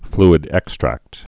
(flĭd-ĕkstrăkt)